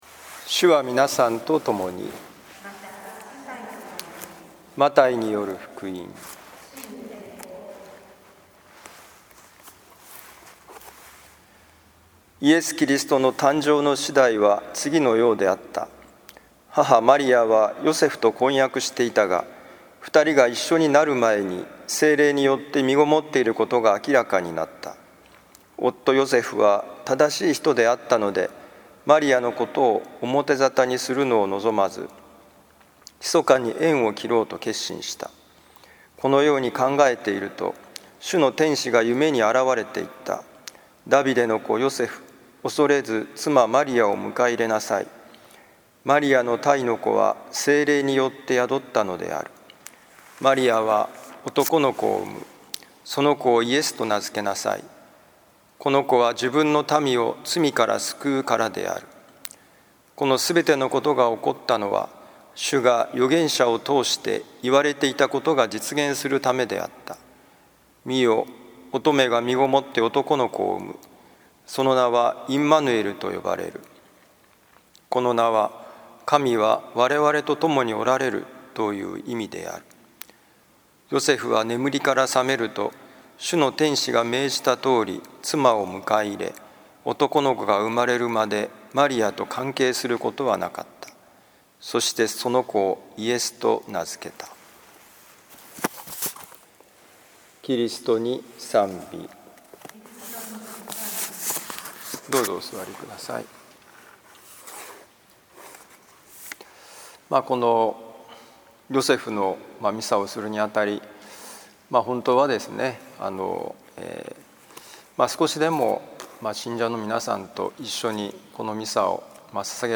マタイ福音書1章18-25節「困難の時にこそーヨセフの生き方」労働者ヨセフの祝日2021年5月1日聖イグナチオ教会 今日の福音書朗読とお説教の聞きどころ